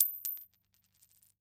household
Coin Nickel Dropping on Cement